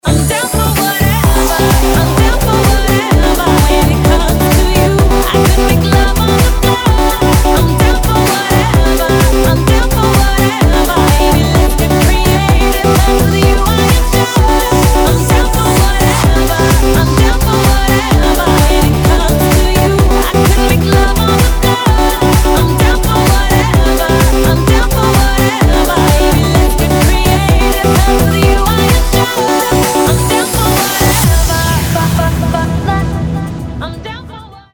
Teraz prichádza s novým tanečným singlom.